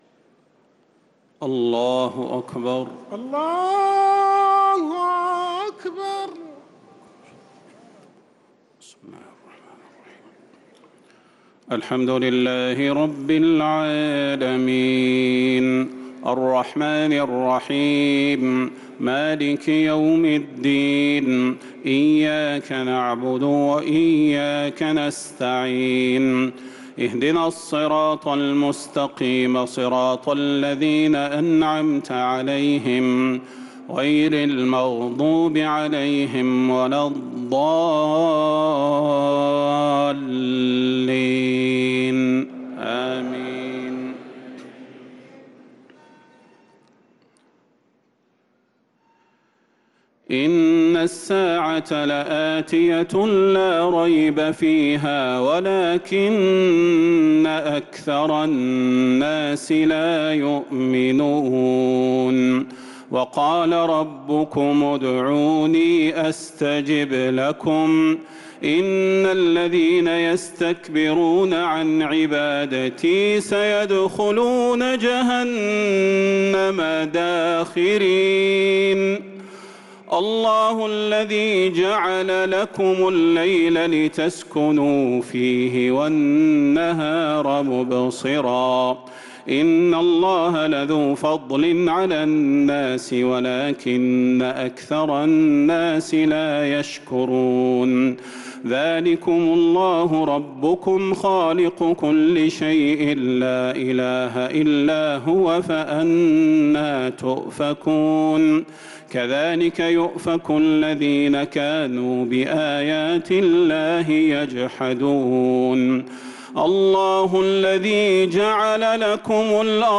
تهجد ليلة 25 رمضان 1446هـ من سورتي غافر (59-85) و فصلت (1-54) | Tahajjud 25th niqht Ramadan 1446H Surah Ghafir and Fussilat > تراويح الحرم النبوي عام 1446 🕌 > التراويح - تلاوات الحرمين